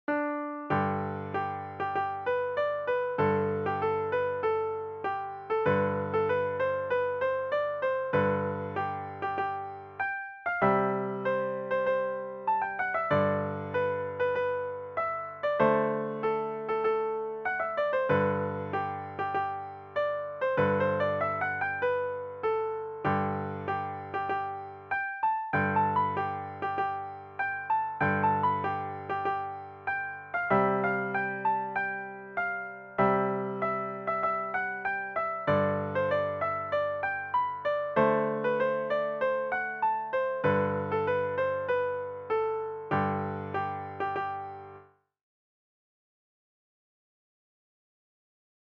DIGITAL SHEET MUSIC - PIANO SOLO
Patriotic, American Music